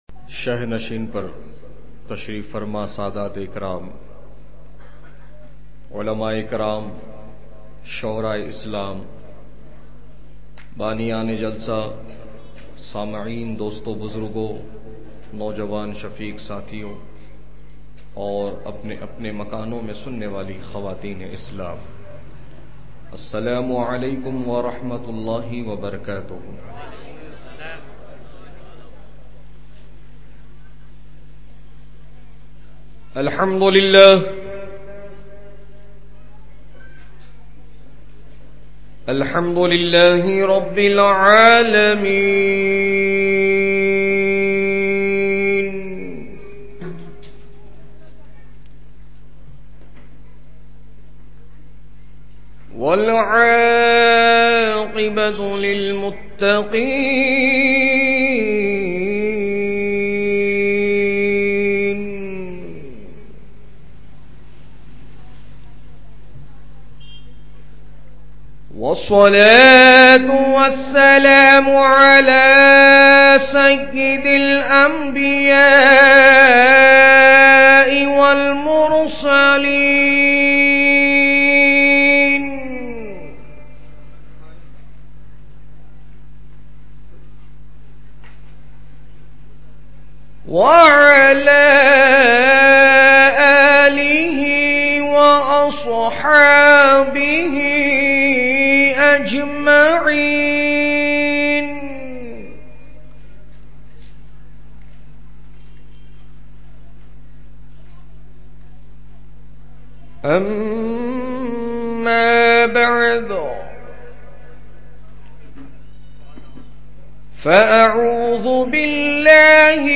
Ramzan Ki Fazilat Aur Barkat Full Bayan